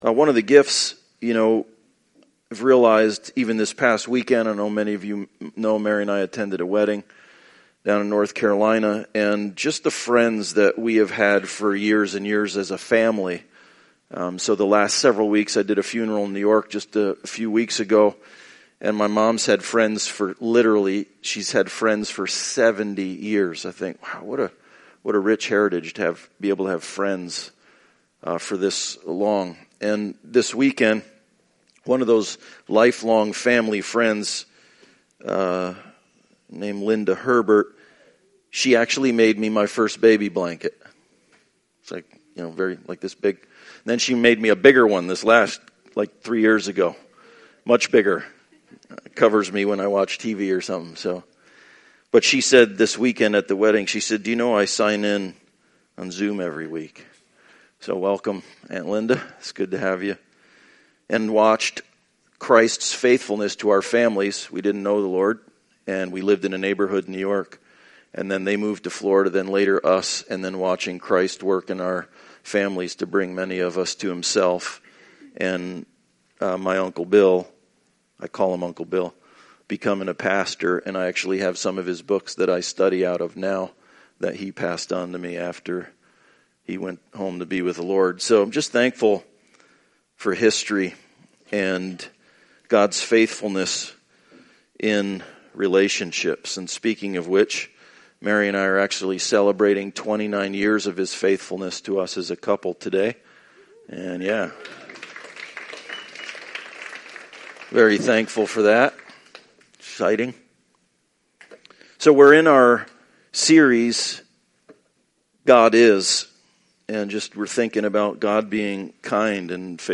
Passage: Ephesians 2:4-5 Service Type: Sunday Service « Identity Formation – “Saints” Identity Formation; Forge our Identity by Believing God!